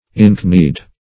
inkneed - definition of inkneed - synonyms, pronunciation, spelling from Free Dictionary Search Result for " inkneed" : The Collaborative International Dictionary of English v.0.48: Inkneed \In"kneed`\, a. See Knock-kneed .